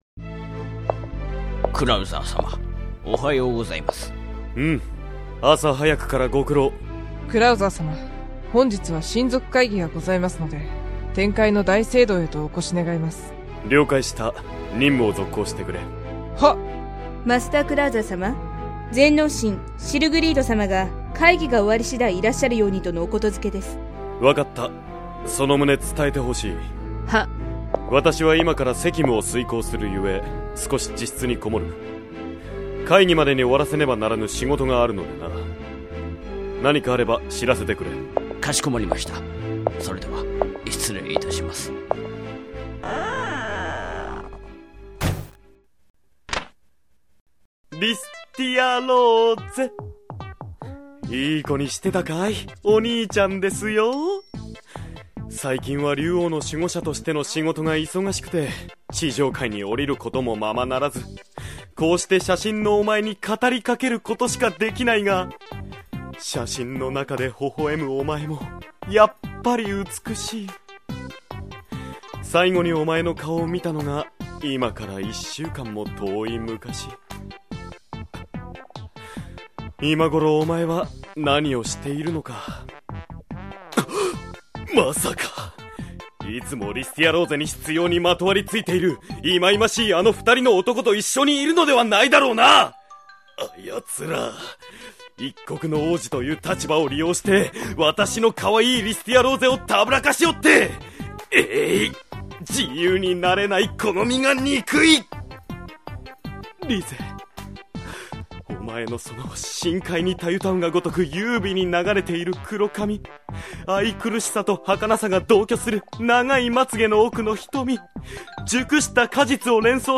ボイスドラマ 登場キャラクター 試聴時間 容量